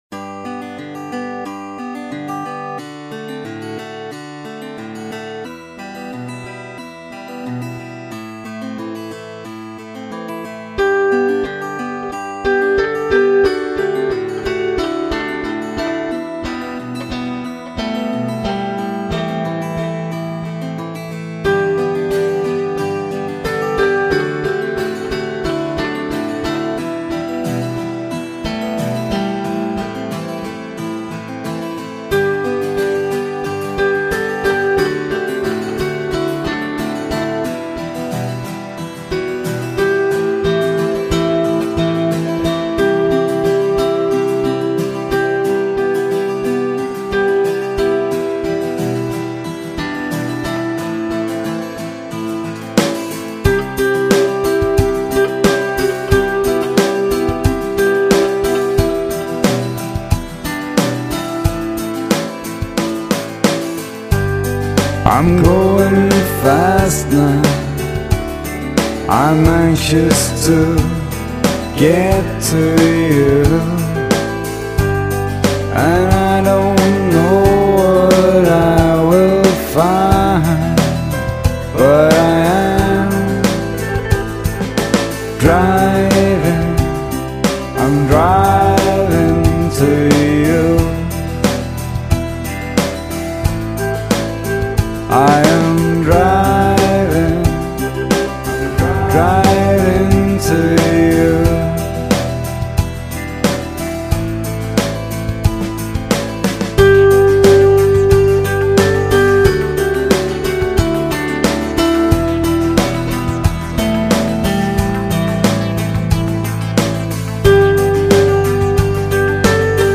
(Pop)